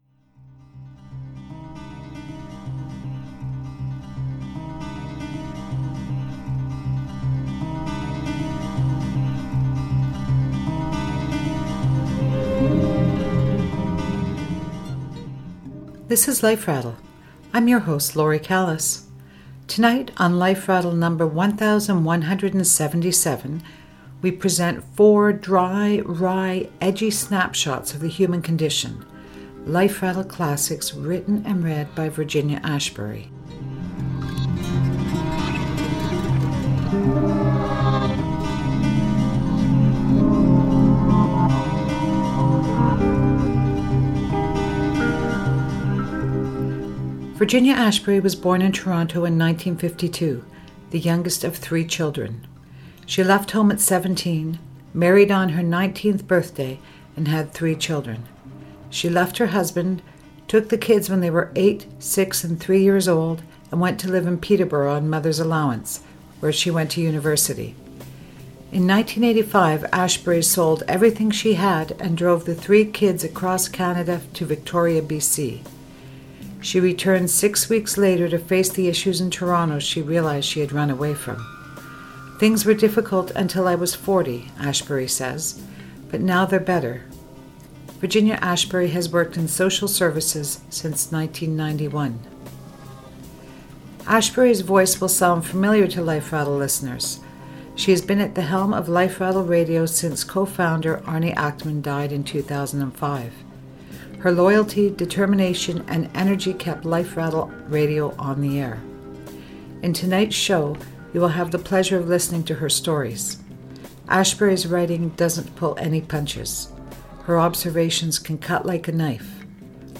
reading her stories